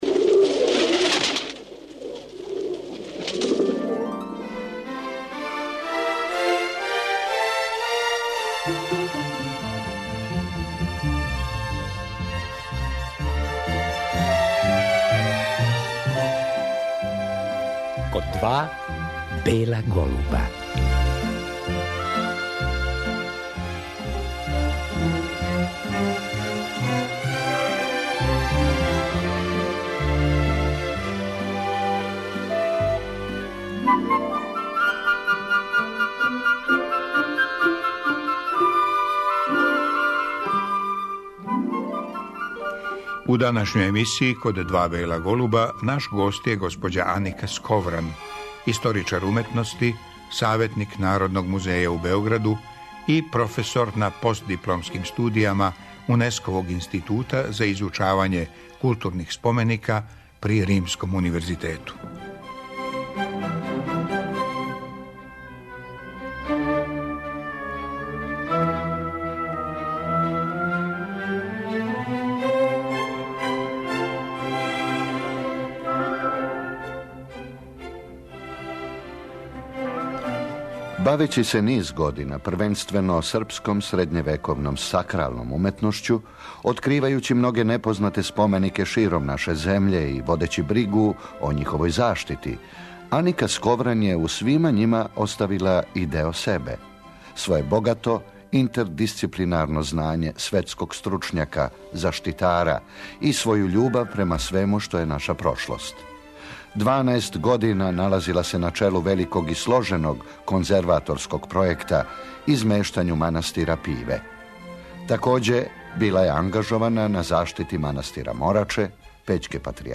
Тим поводом слушаћемо разговор